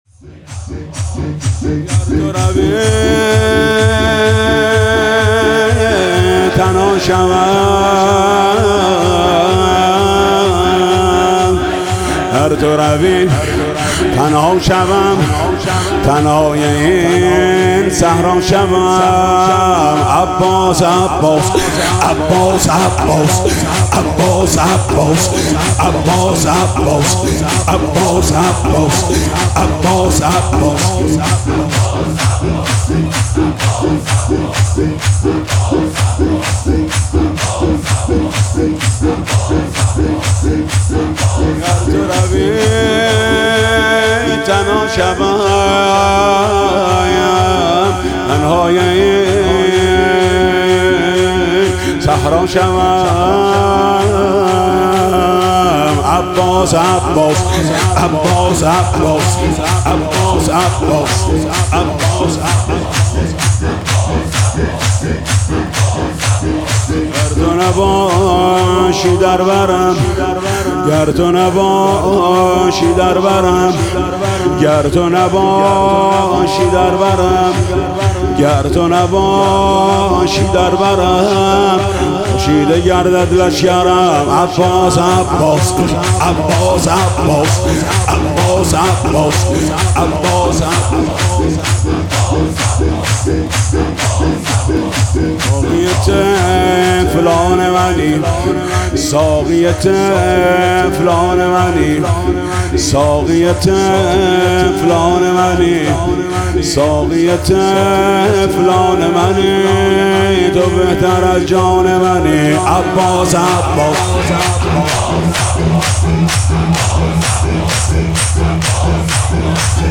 مراسم شب تاسوعای حسینی در هیئت رایة العباس با مداحی محمود کریمی برگزار شد.
سینه‌زنی (شور)